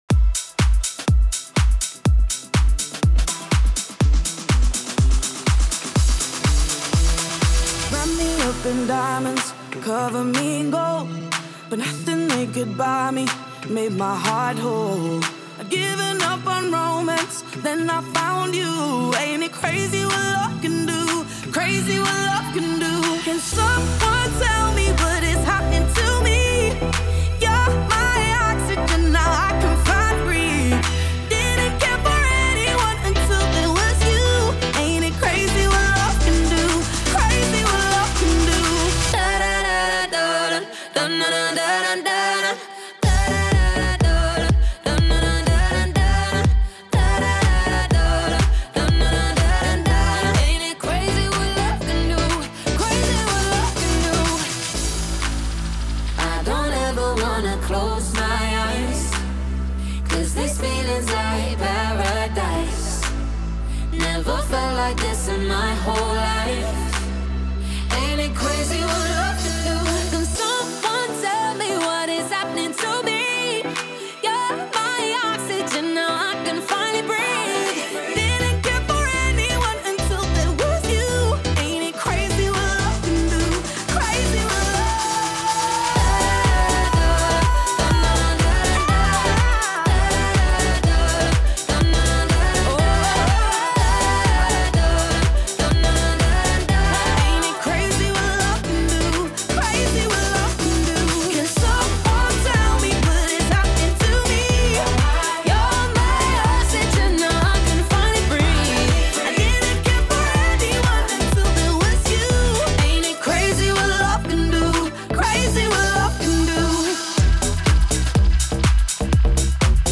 A live EDM and dance mix